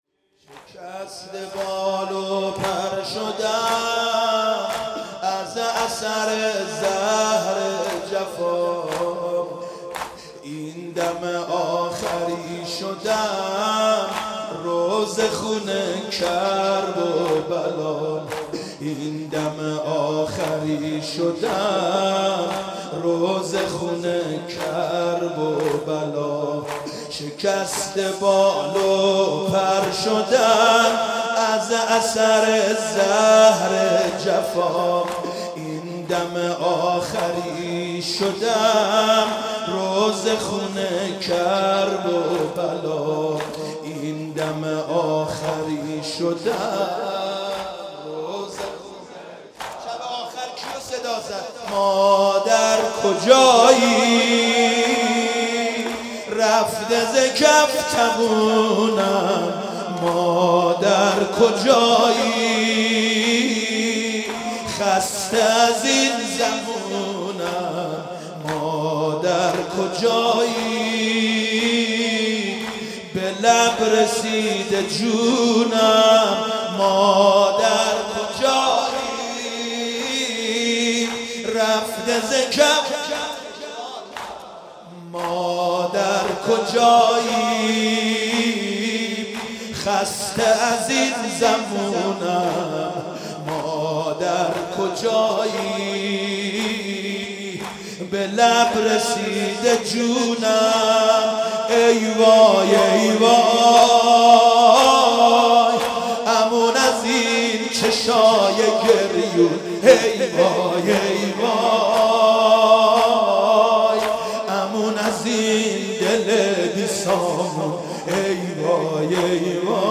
شهادت امام باقر (ع) 95 - زمینه - شکسته بال و پر
محمود کریمی زمینه مداحی